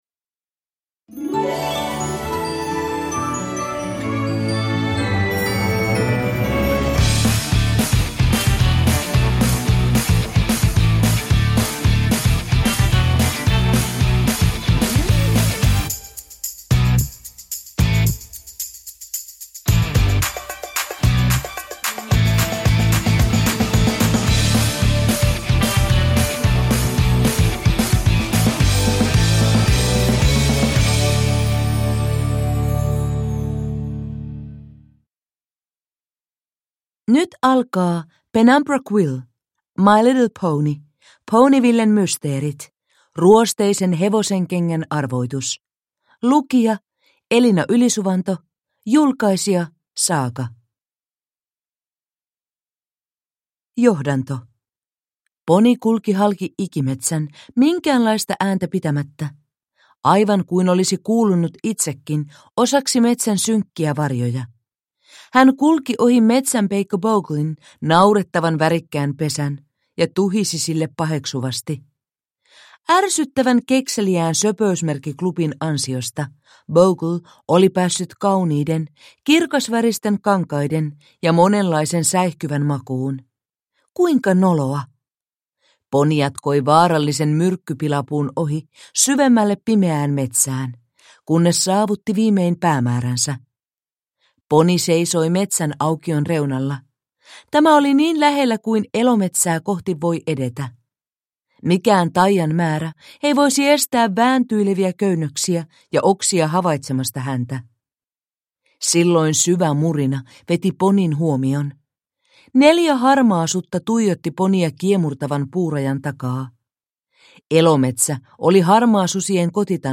My Little Pony - Ponyvillen Mysteeri - Ruosteisen hevosenkengän arvoitus (ljudbok) av Penumbra Quill